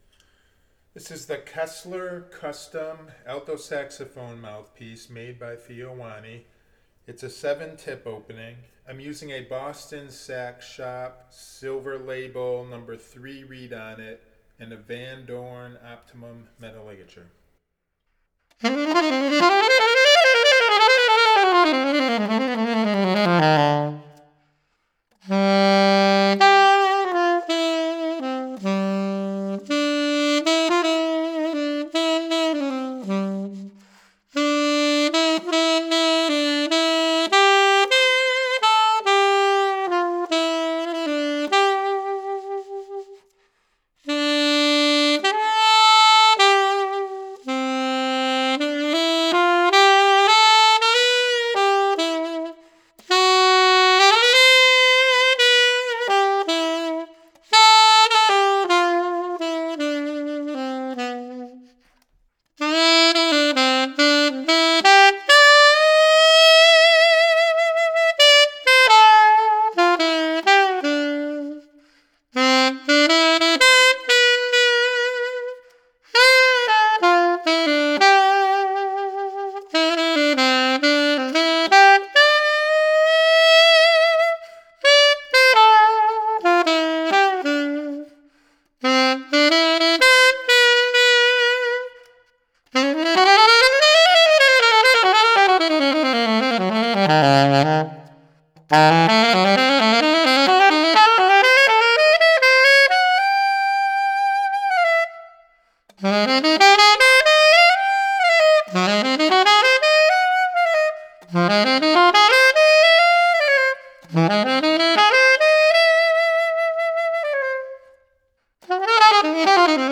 The low notes could be sub-toned easily to produce a beautiful lush sound down low.
The first sound clip is recorded with some reverb added on a BSS (Boston Sax Shop) #3 silver label alto saxophone reed.
The reverb seems to fatten up the natural brightness of the alto saxophone.
Kessler Custom NY7 Alto Saxophone Mouthpiece by Theo Wanne – Reverb Added-BSS (Boston Sax Shop) #3 Silver Label Reed
KesslerCAlto7-Reverb.mp3